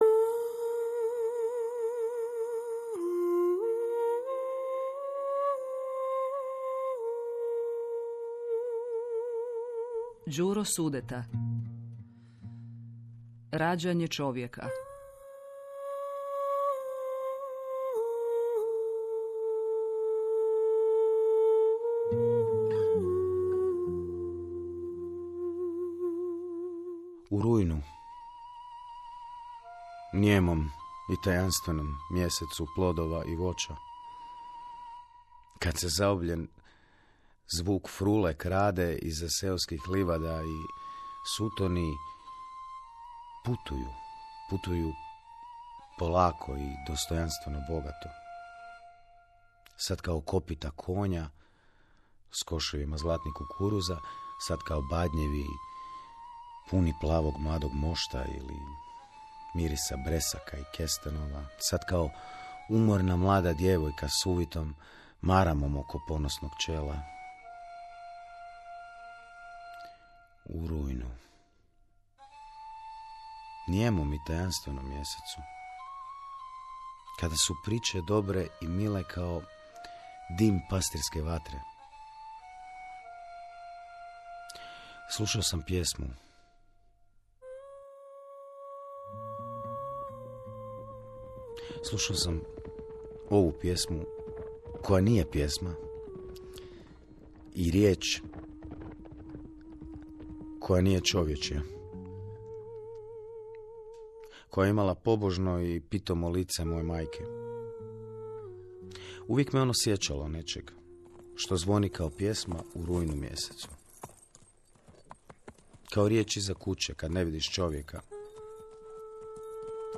Radio drama – Podcast
Drame i dramatizacije stranih klasika širokog vremenskog raspona, te suvremena europska i hrvatska drama klasičnog prosedea. Glumačke ekipe okupljene u ovim zahtjevnim radiofonskim projektima najzvučnija su imena hrvatskog glumišta koja na radiju nalaze svoje umjetničko utočište.